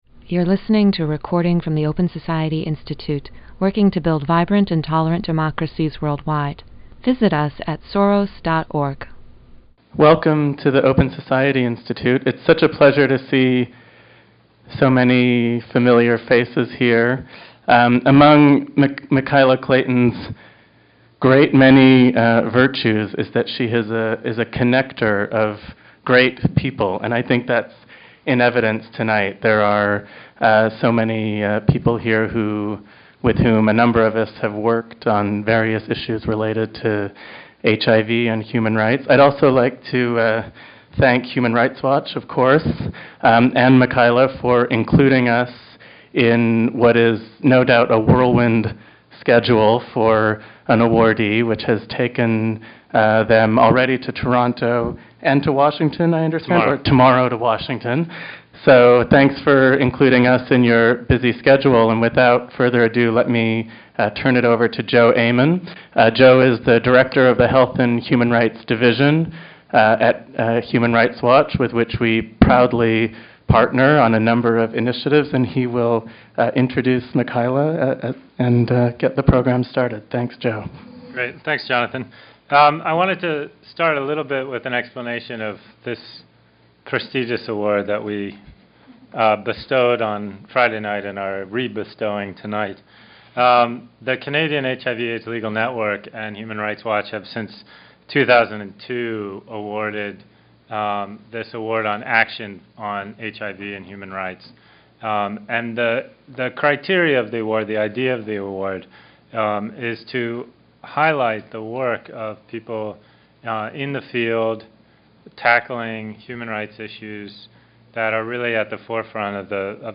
Note: The audio for this event has been edited.